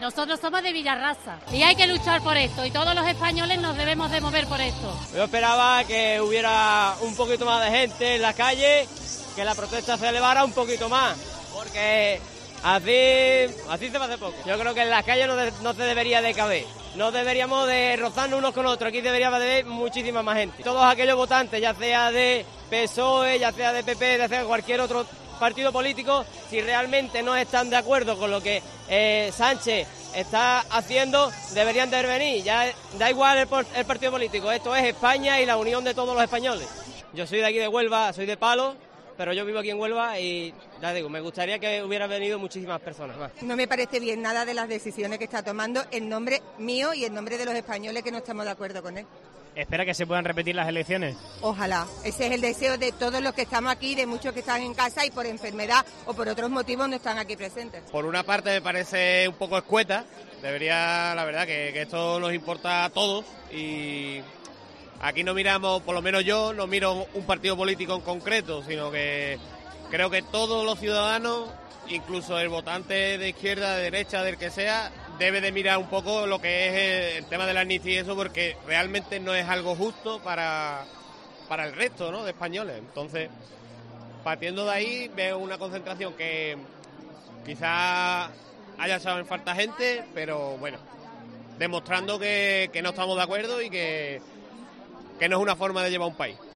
Testimonio de manifestantes contra la amnistía